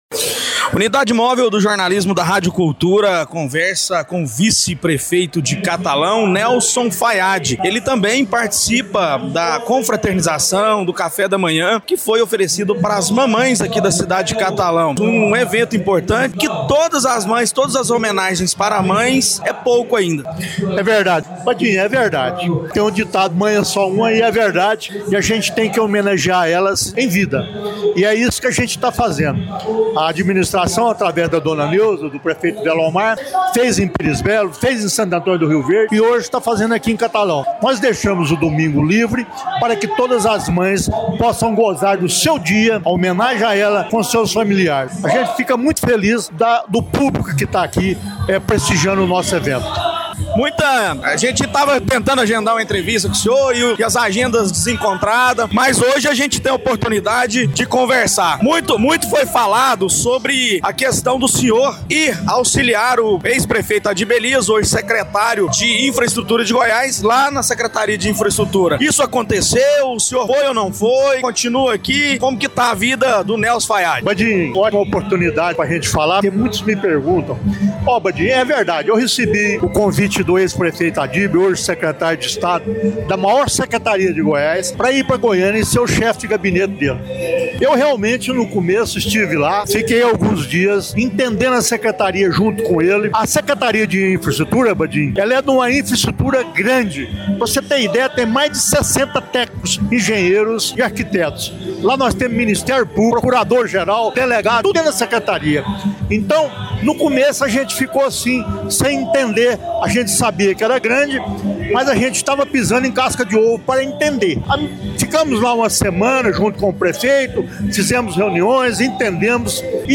Entrevista-Nelson-Faiad-online-audio-converter.com_.mp3